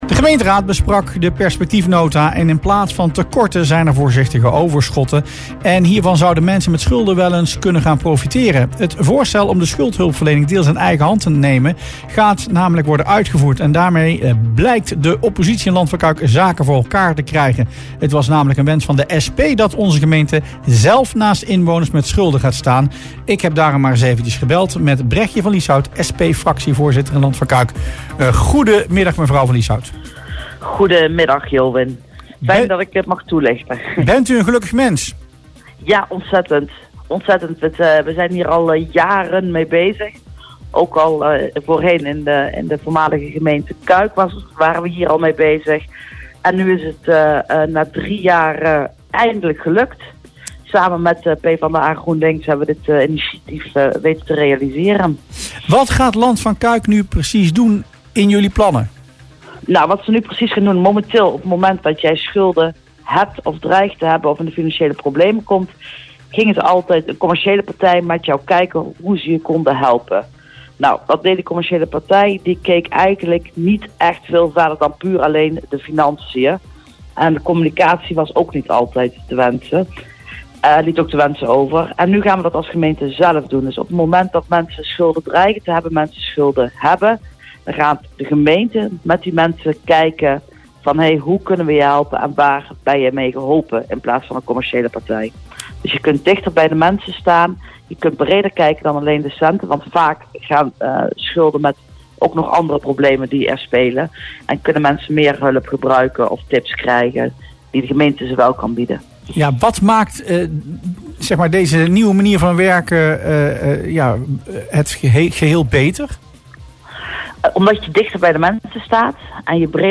SP-fractieleider Bregje van Lieshout reageerde verheugd in het radioprogramma Rustplaats Lokkant.
Bregje van Lieshout (SP) in Rustplaats Lokkant